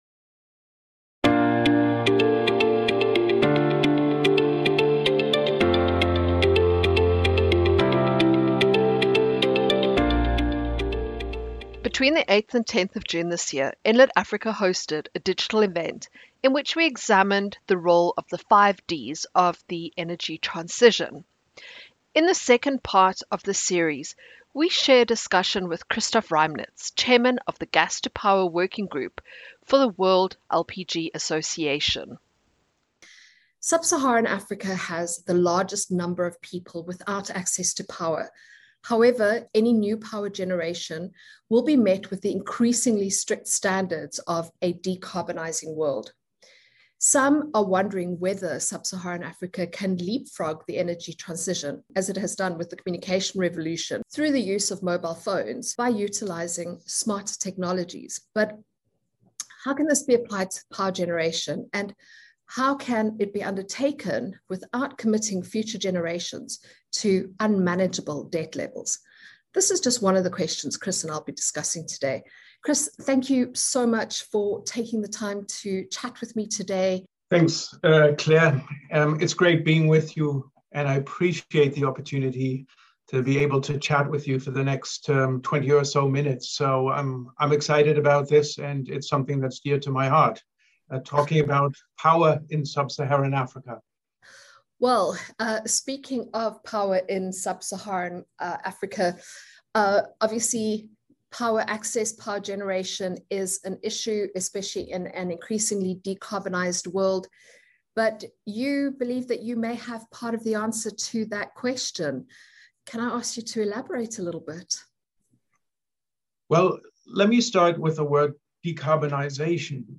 LPG to power - a conversation